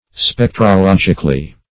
-- Spec`tro*log"ic*al*ly , adv.